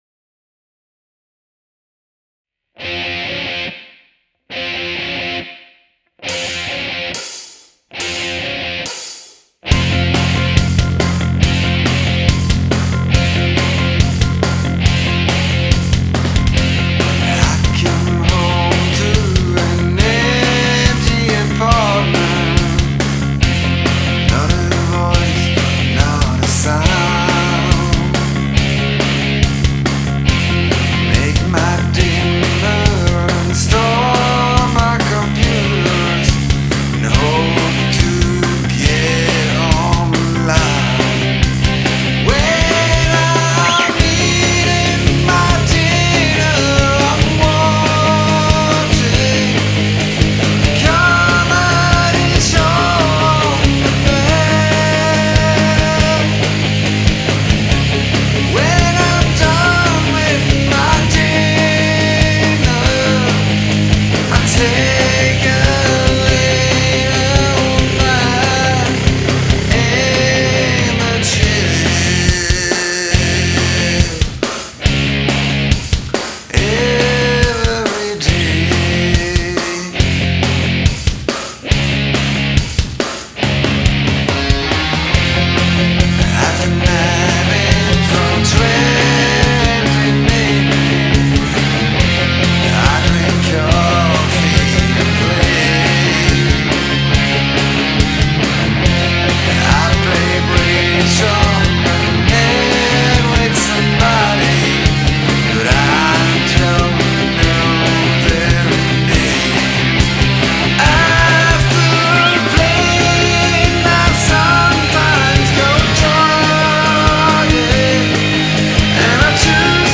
Mix a rock song with blues theme...?
The drums are a bit dry.
vocals sound good i like the tone of your vox.